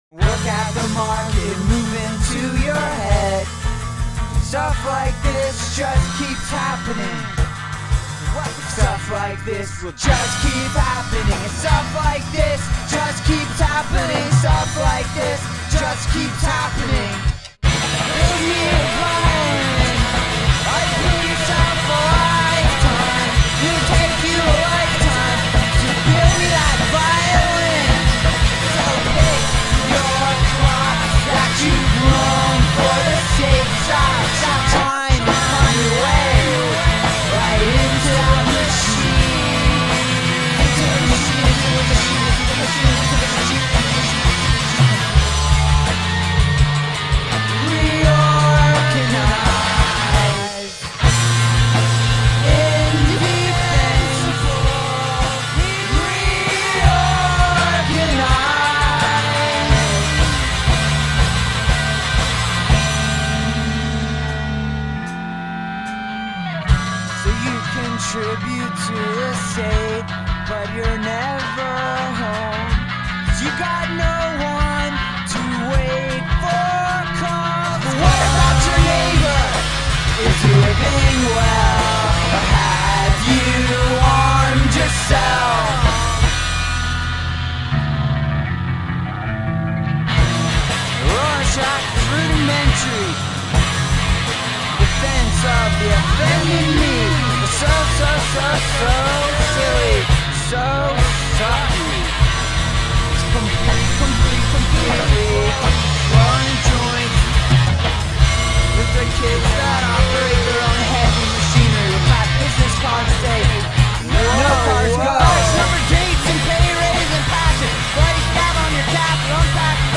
bass
drums
guitar + vocals
an early demo version plus added vocals.